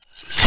casting_charge_matter_fast_03.wav